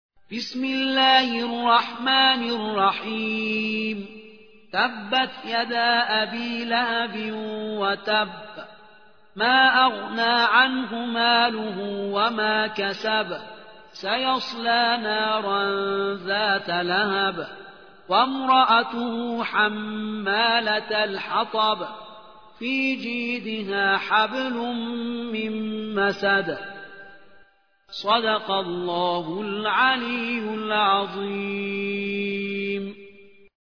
سورة المسد / القارئ